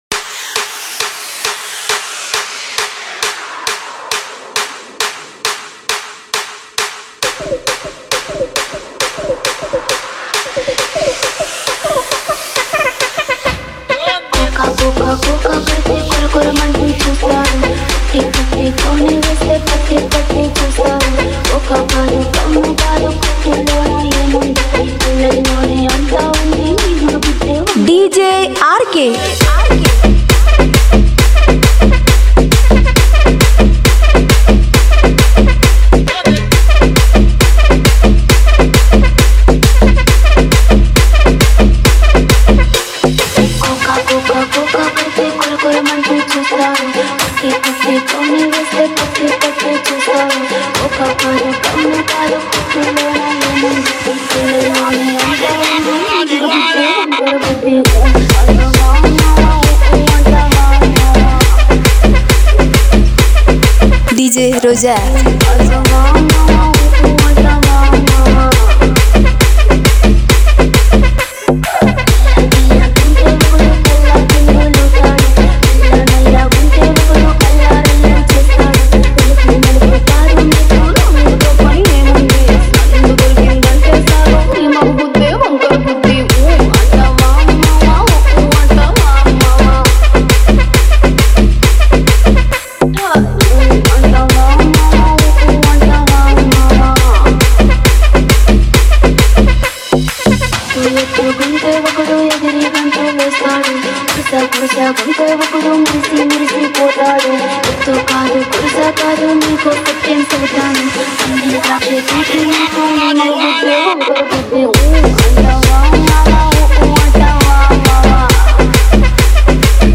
Telug Dj Collection 2022 Songs Download